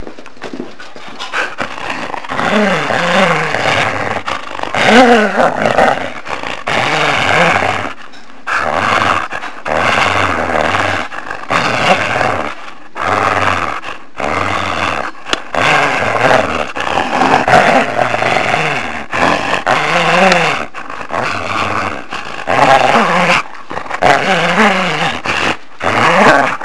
Grrr!
I was looking after my cousins' dog Sally recently and thought I'd record her growling during a tug-of-war with a ring: Perhaps a good sound for a new AI when it's angry.
Growling Dog (~300kb, 26sec)